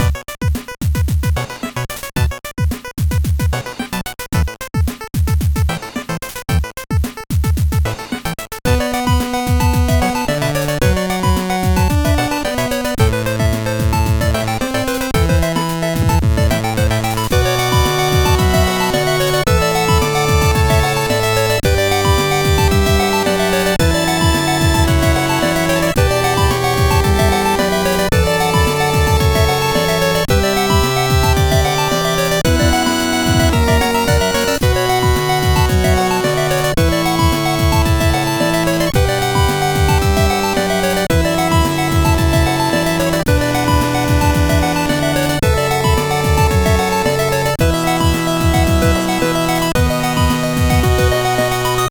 NES